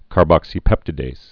(kär-bŏksē-pĕptĭ-dās, -dāz)